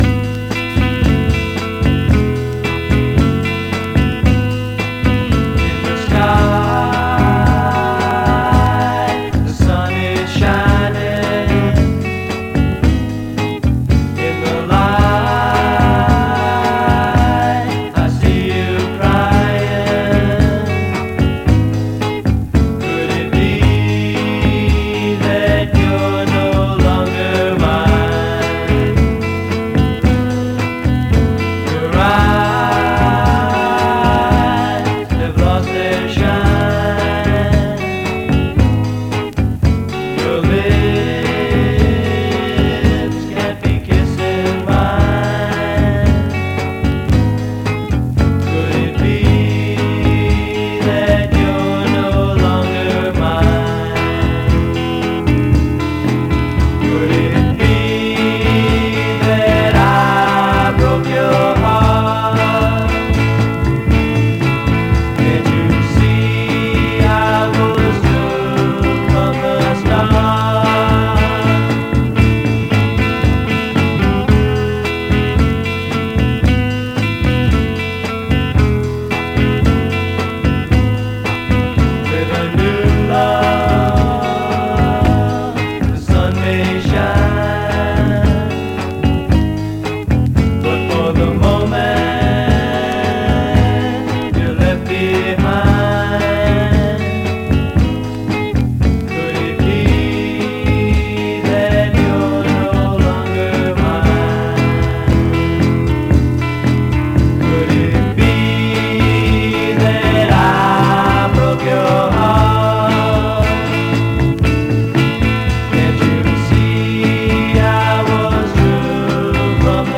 his old band: